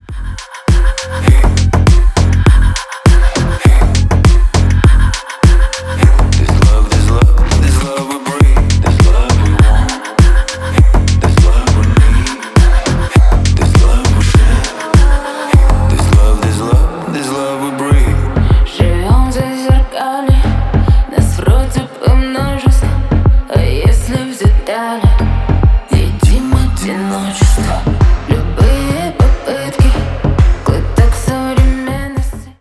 клубные , поп , ремиксы